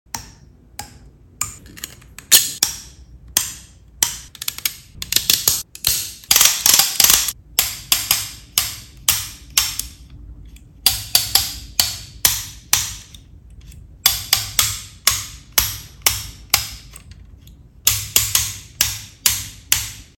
3D Printed Ratchet Fidget sound effects free download